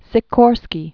(sĭ-kôrskē), Igor Ivan 1889-1972.